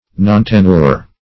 Search Result for " nontenure" : The Collaborative International Dictionary of English v.0.48: Nontenure \Non*ten"ure\, n. (Law) A plea of a defendant that he did not hold the land, as affirmed.
nontenure.mp3